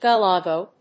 音節ga・la・go 発音記号・読み方
/gəléɪgoʊ(米国英語), gəléɪgəʊ(英国英語)/